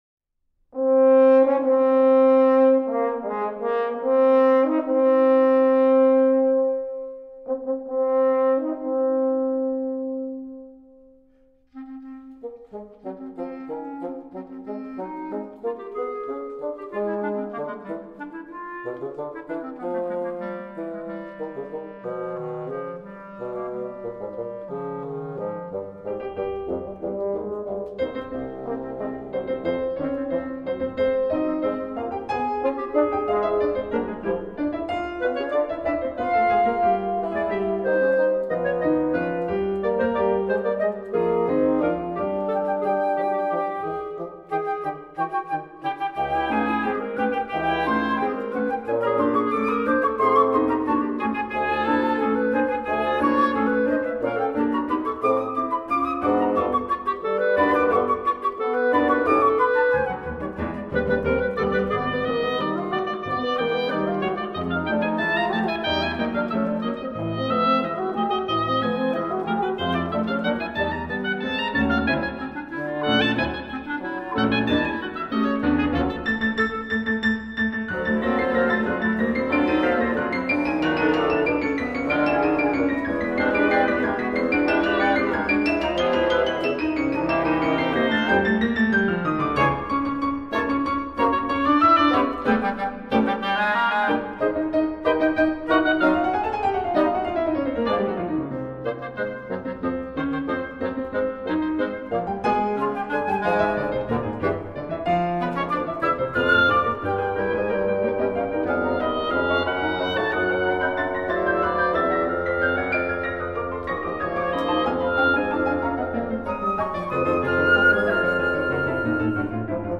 A three-movement sextet filled with verve and melodic charm.